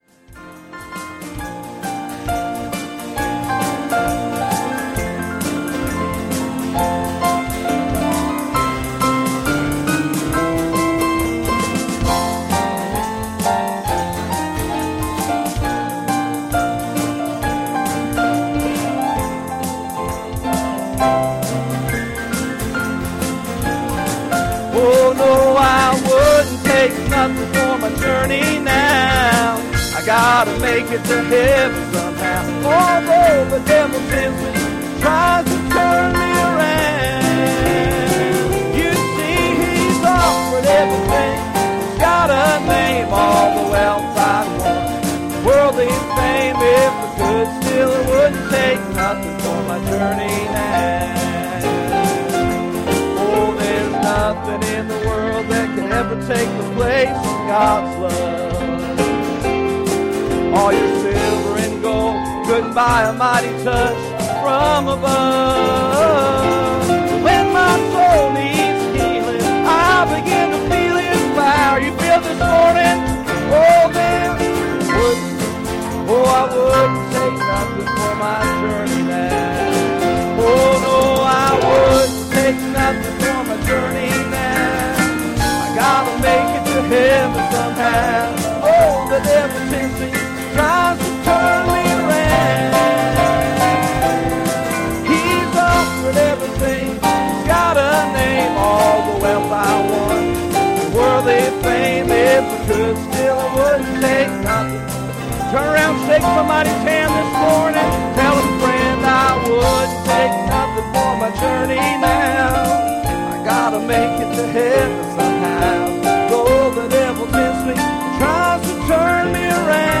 Passage: 1 Kings 18:21 Service Type: Sunday Morning